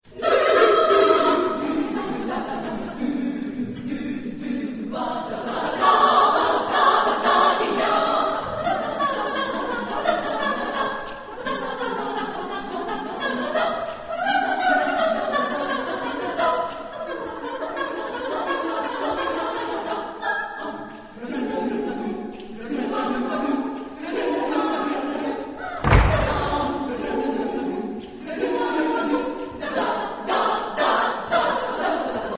Sprache: Tonsilben
Genre-Stil-Form: Unterhaltungsmusik ; weltlich
Chorgattung: SSSSAAAA  (8 Frauenchor Stimmen )
Tonart(en): A-Dur